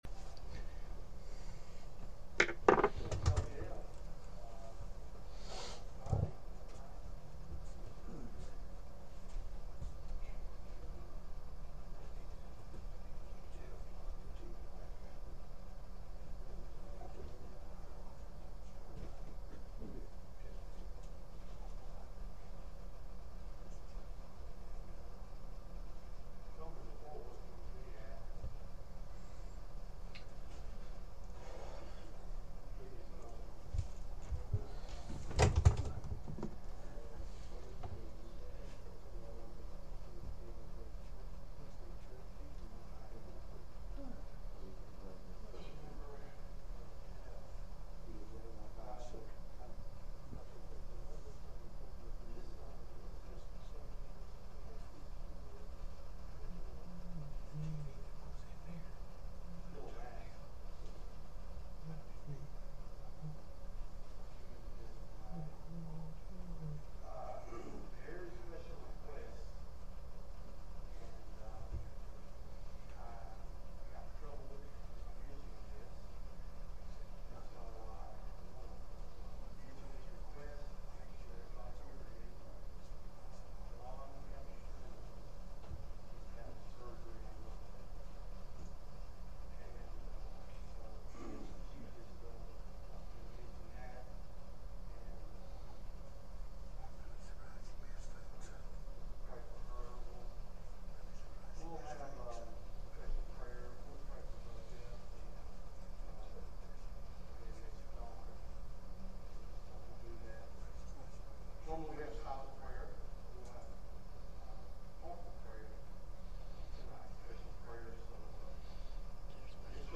1 Peter 2:9-10 Service Type: Midweek Meeting « That Homecoming Day